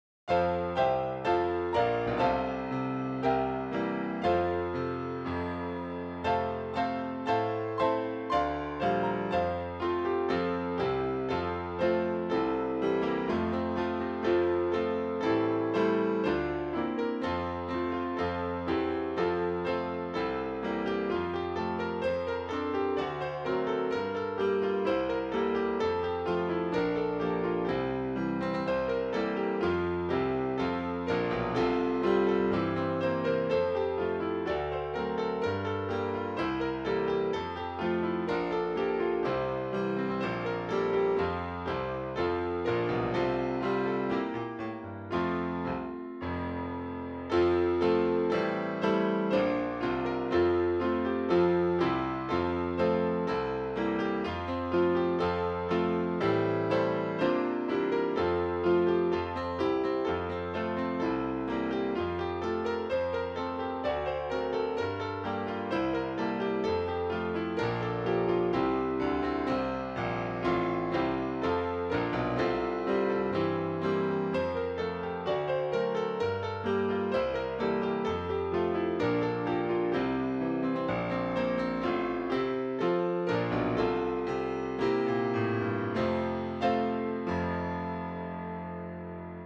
French melody
Key: F Meter: 7.7.7.7 R